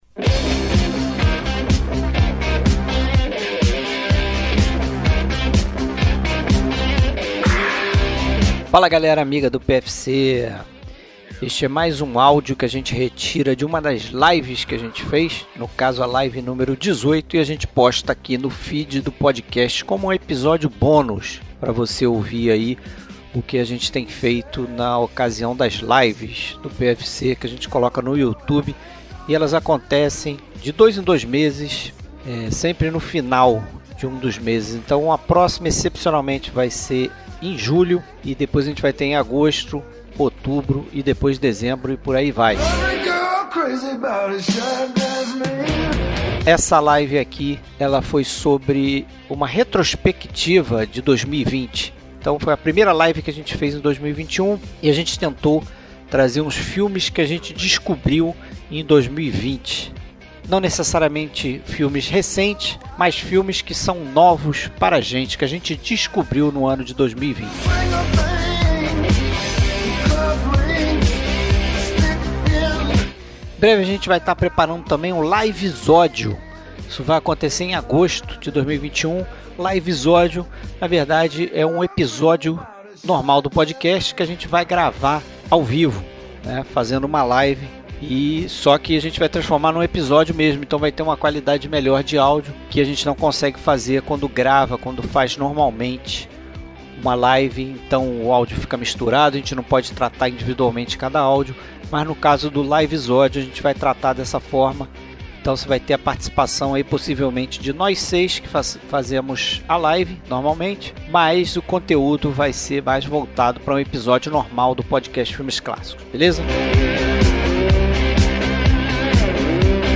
Áudio da "Live" número 018 em nosso canal no Youtube. Cada um de nós traz para o debate cinco filmes que viu pela primeira vez no ano de 2020, venha coletar ótimas dicas nessa retrospectiva cinéfila do Podcast Filmes Clássicos!